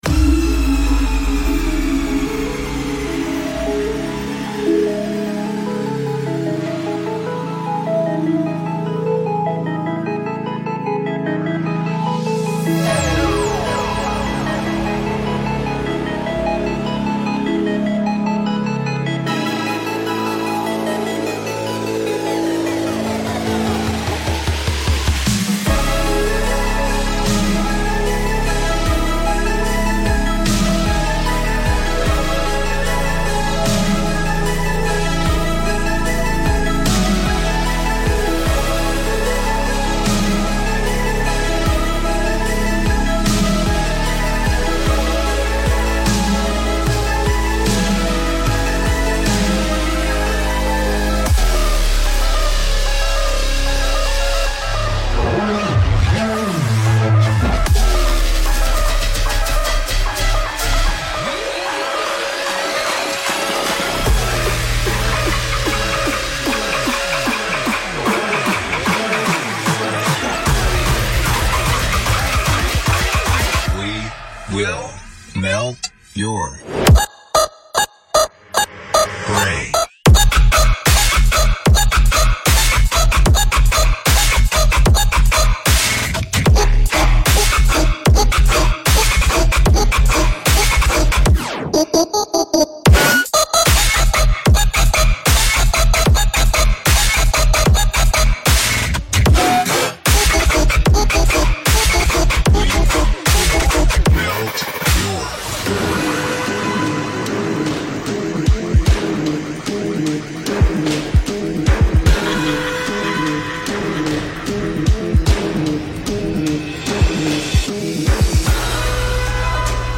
Genre: House